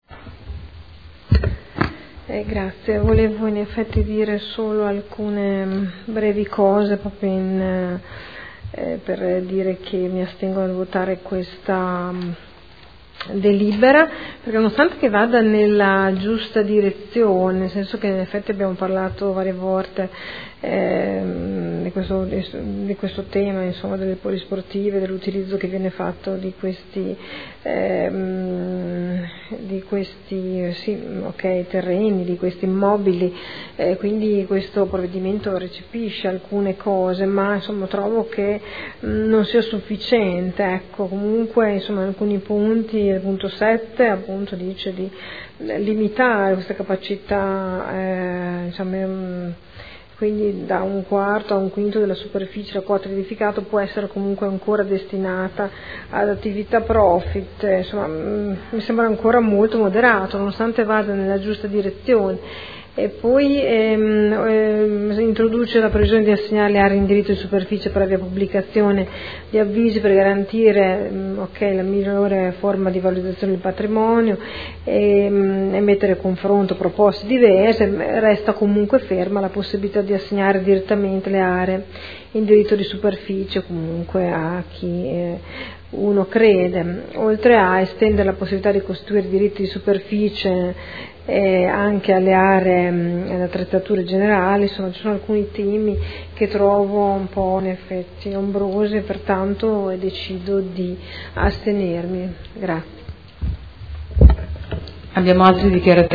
Seduta del 7 aprile. Proposta di deliberazione: Regolamento comunale per la concessione in D.D.S. di aree comunali – Modifica. Dichiarazioni di voto